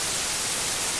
static.ogg